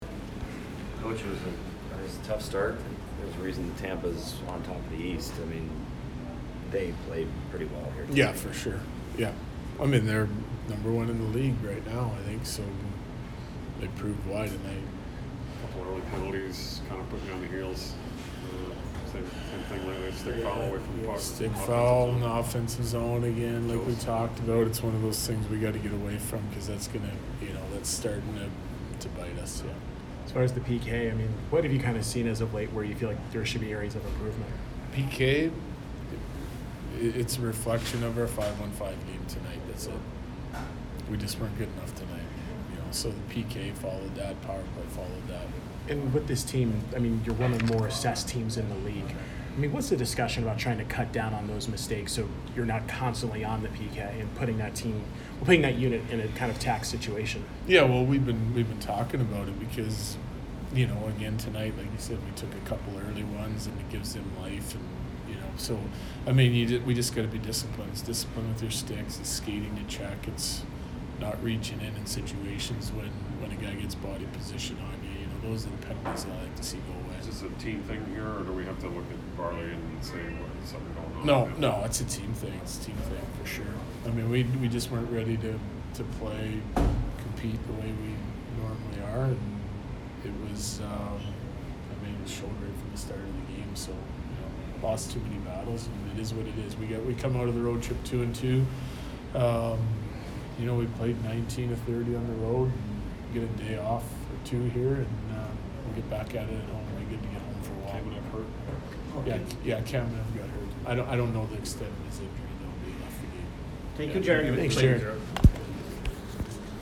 Jared Bednar post-game 12/08